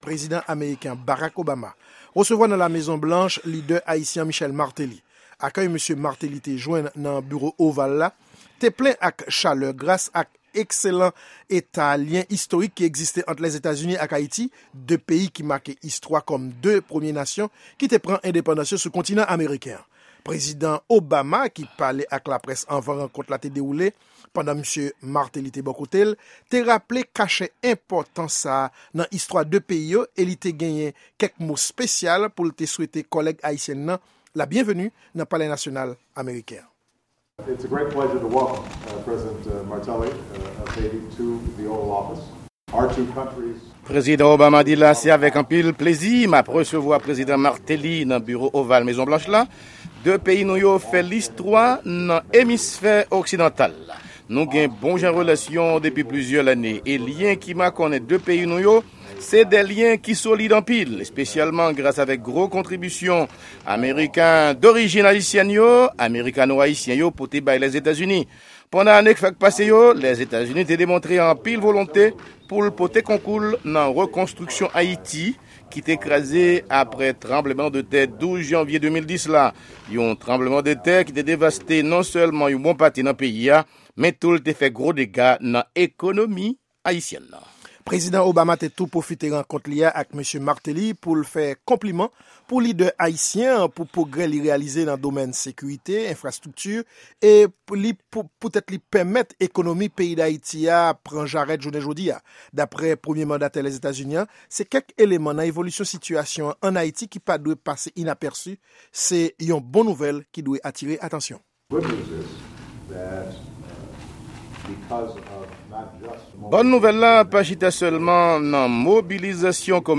Repòtaj sou Somè Obama-Martelly nan La Mezon Blanch - Jedi 6 fev. 2014